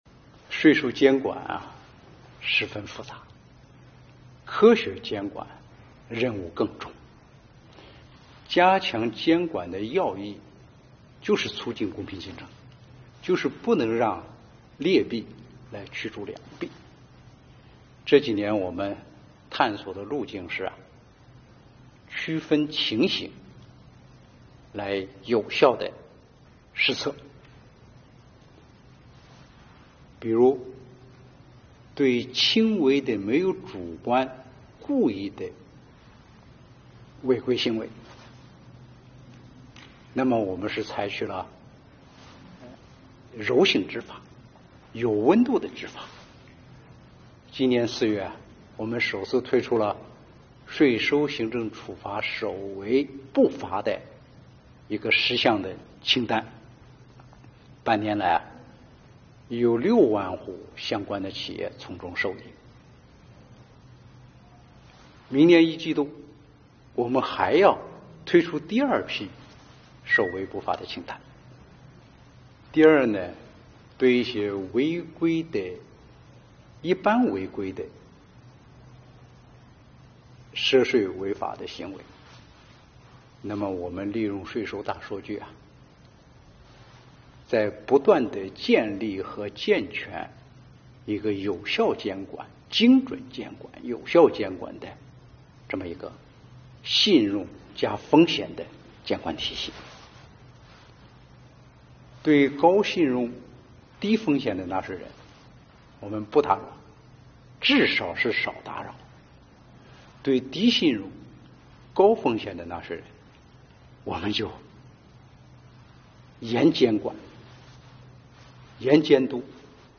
经济日报记者就此采访了国家税务总局局长王军。